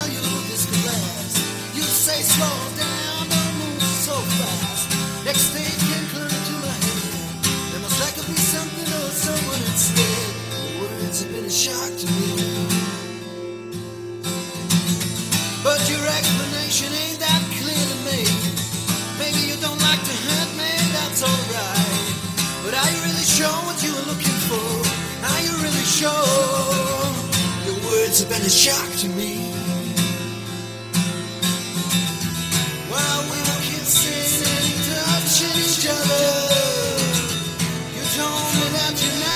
Too far out. vocals, acoustic guitar, Telemaster